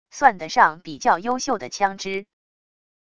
算得上比较优秀的枪支wav音频